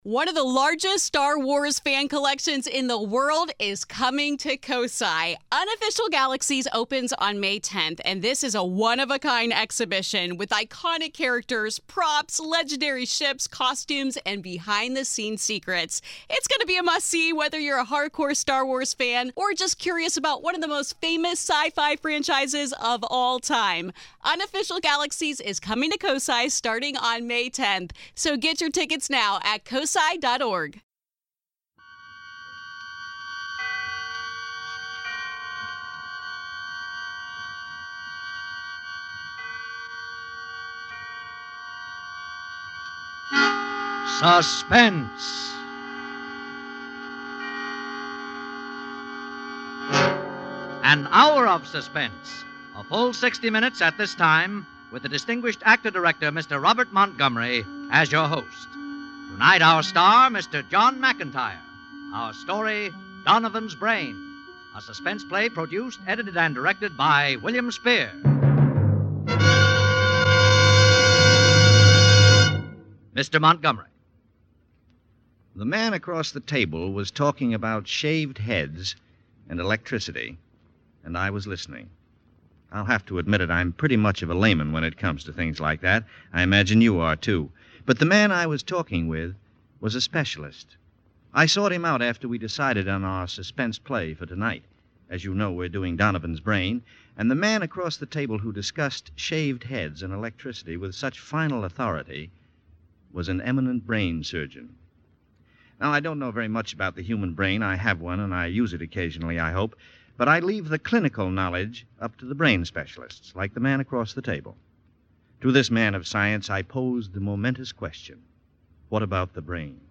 On this episode of the Old Time Radiocast we present you with a special hour long presentation of the classic radio program Suspense!